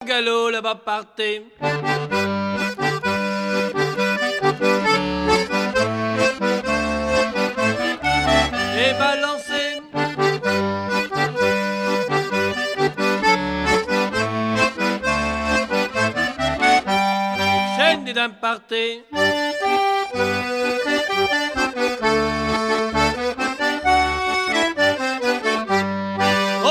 danse : quadrille : galop
Pièce musicale éditée